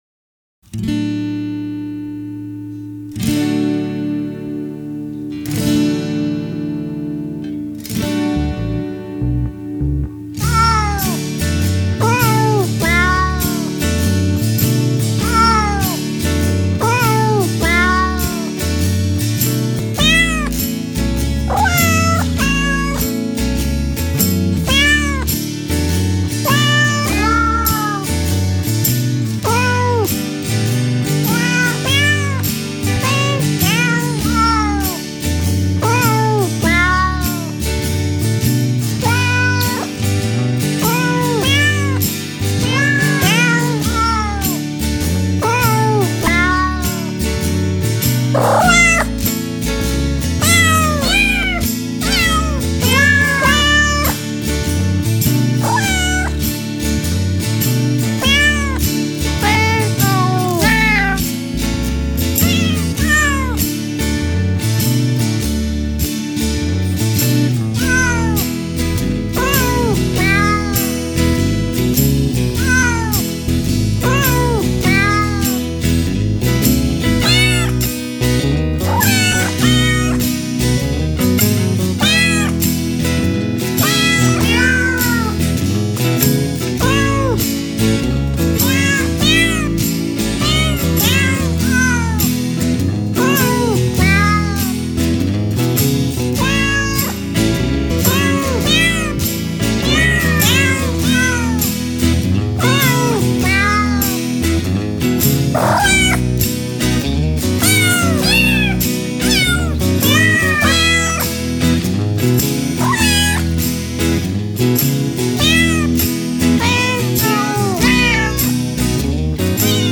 我一开始还没听出来，后来对比了一下，才找到原作的名字，叫《Silent Night》，原作是很经典的，大家听听看这首如何，（不是问它的经典程度呀） 我找到原作并给它写名字的时候，就开始佩服这首音乐（还算是吧）的作者，太厉害了，厉害到了能和猫一起唱歌的程度了！（我都分不出猫叫声的音高来的），顿时佩服得无体投地。。。。